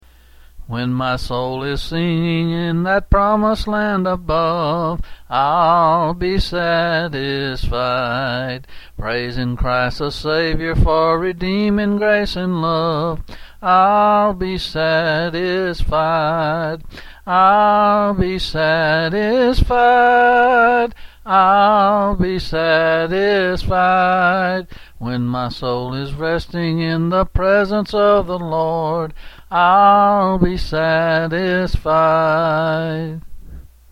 Quill Selected Hymn